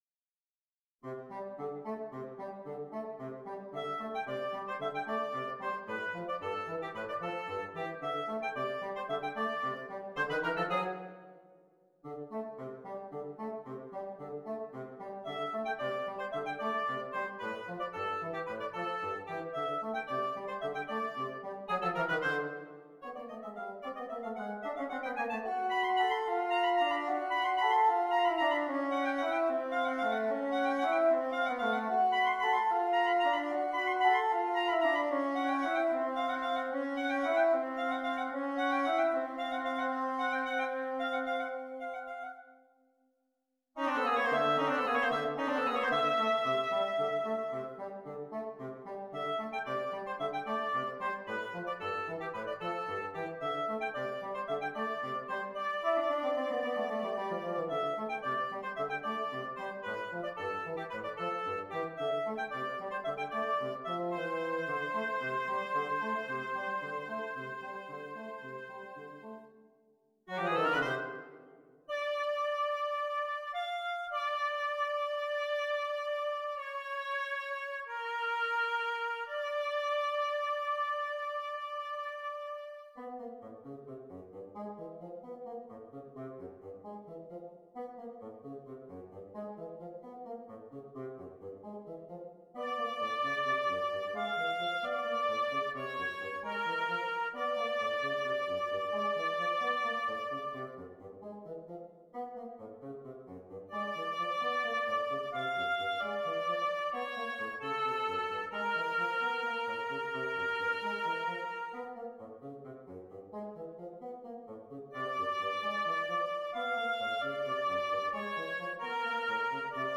Oboe and Bassoon